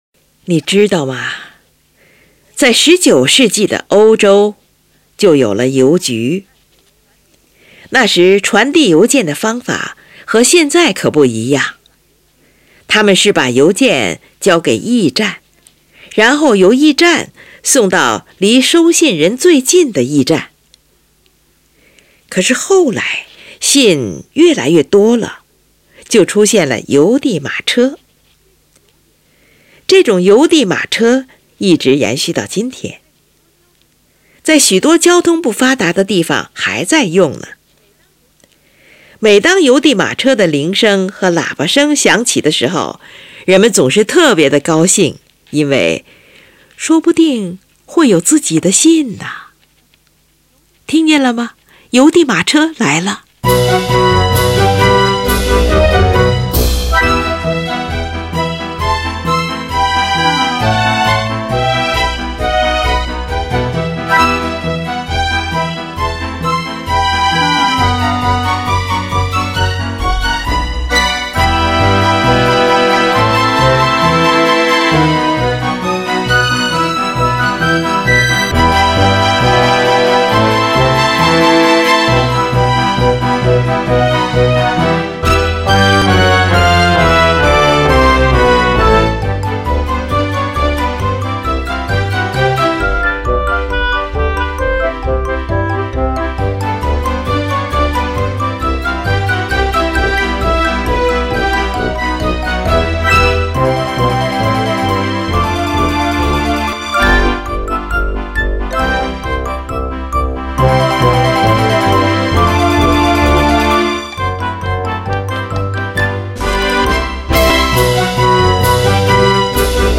中间部分是用一系列八分音符组成的曲调，这是霍普赛曲的最大特点。最后，引子和第一部分A段主题再现，在欢乐的气氛中结束全曲。